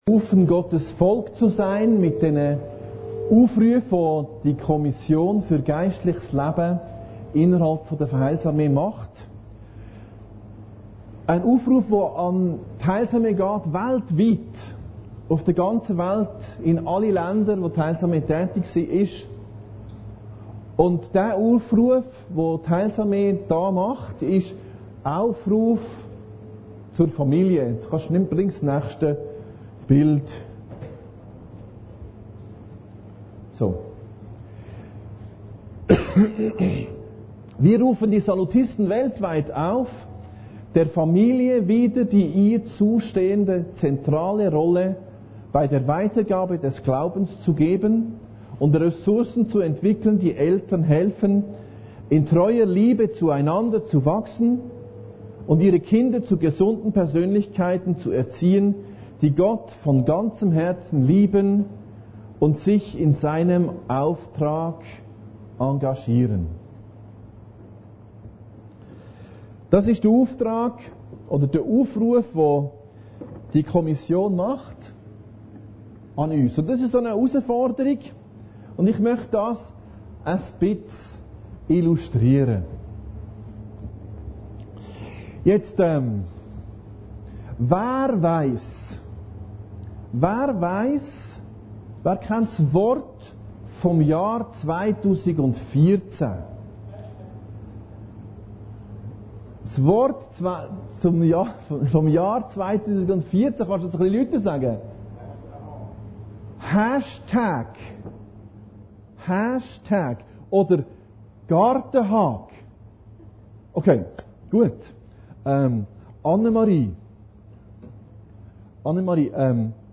Predigten Heilsarmee Aargau Süd – Aufruf zur Familie